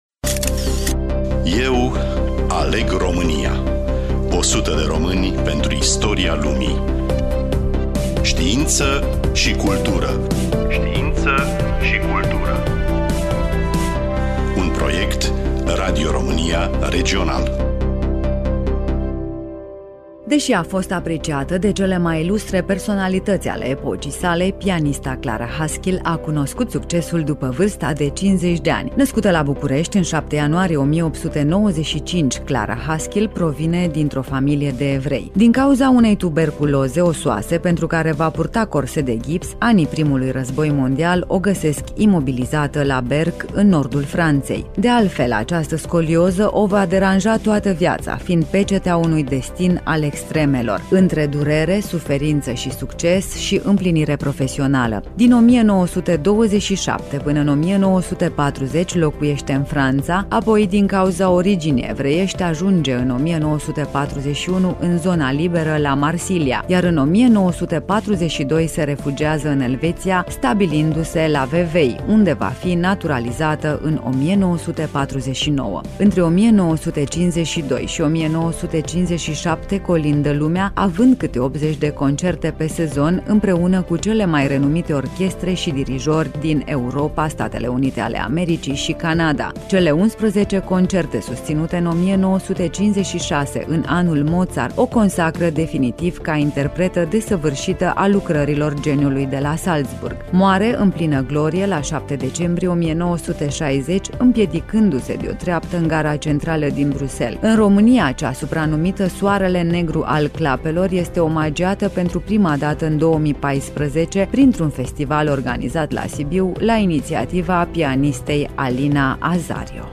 Studioul: Radio România Reşiţa